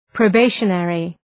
Προφορά
{prəʋ’beıʃə,nerı}